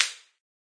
plasticplastic3.ogg